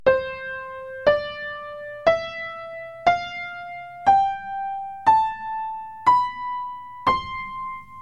도,레,미,…. 는 소리의 높낮이가 다른 것입니다.
piano_doremi_level.mp3